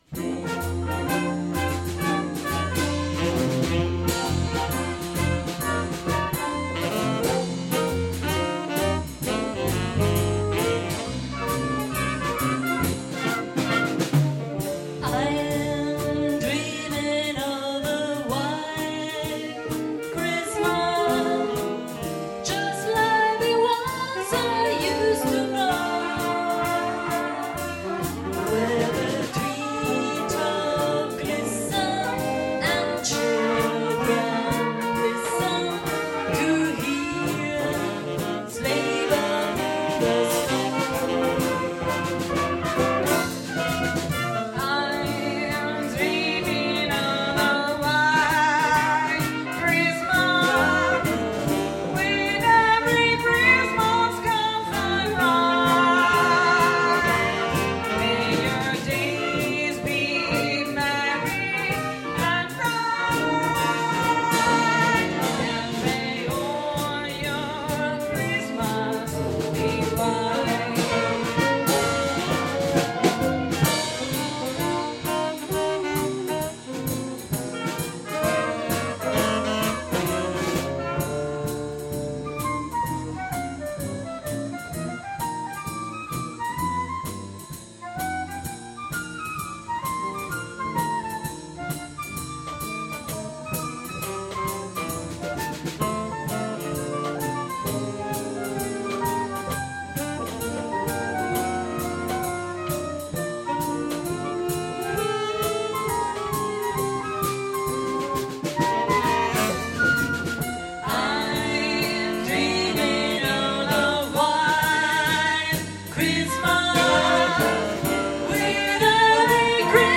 Concerto al Vivaio Riva 20 dicembre 2015